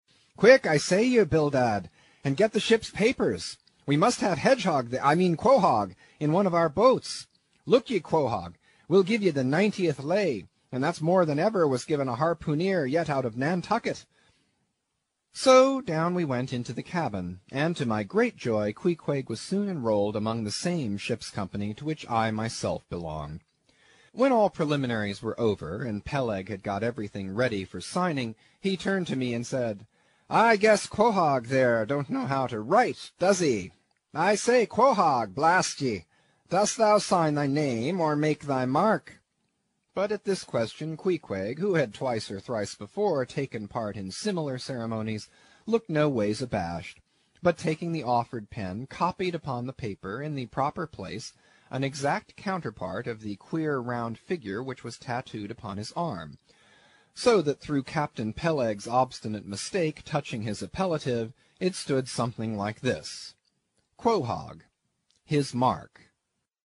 英语听书《白鲸记》第99期 听力文件下载—在线英语听力室